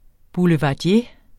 Udtale [ buləvɑˈdje ]